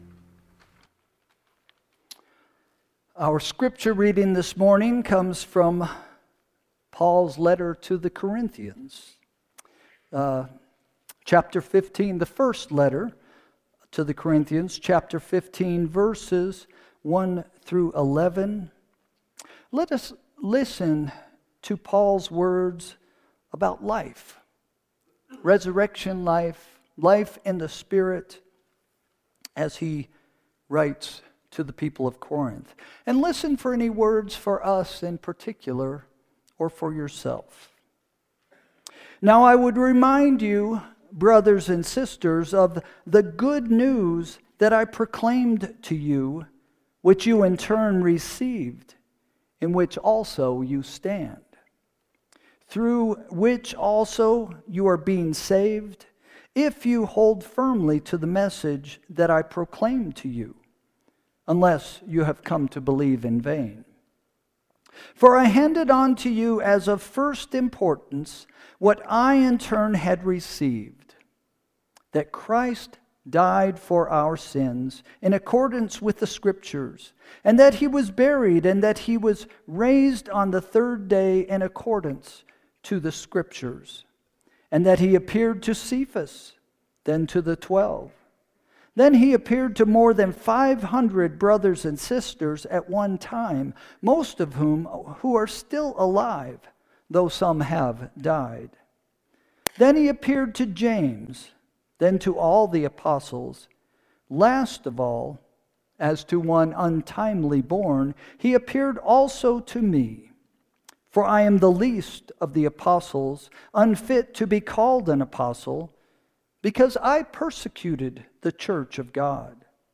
Sermon – June 22, 2025 – “Life is a Choice” – First Christian Church